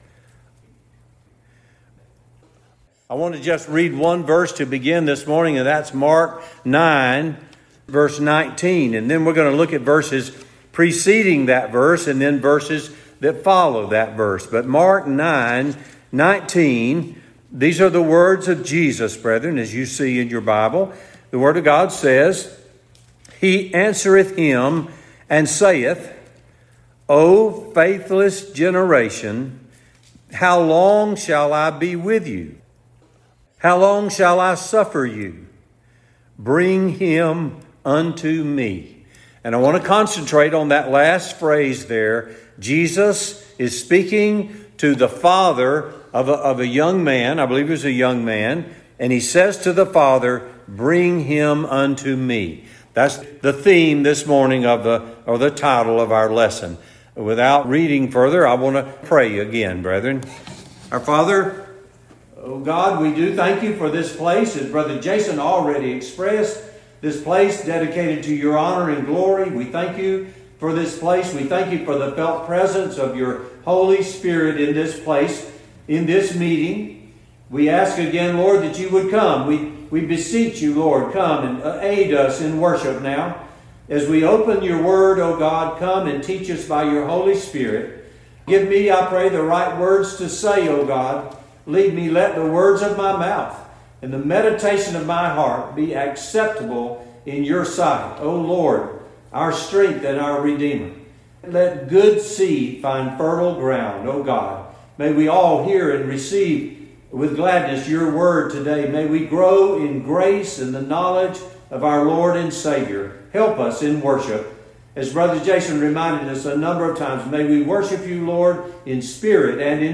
Mark 9:19; Jesus Said, Bring Him Unto Me Oct 13 In: Today's Sermon